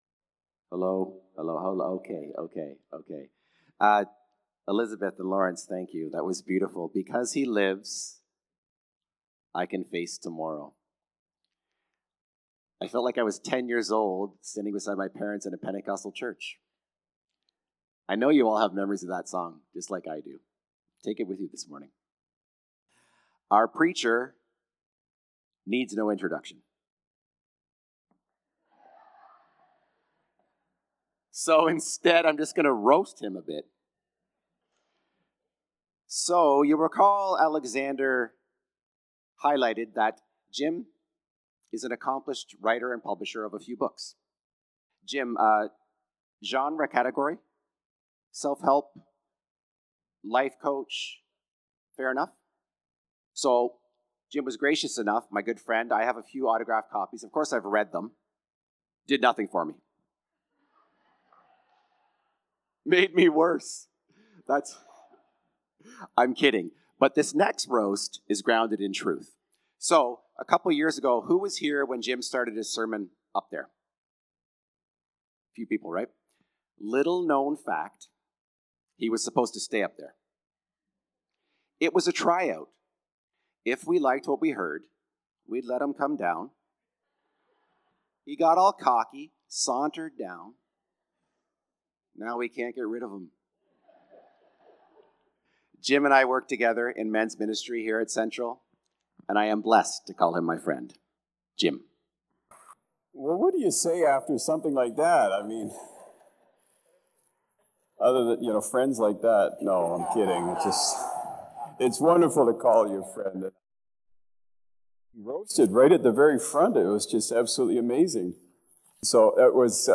August-11-Sermon.mp3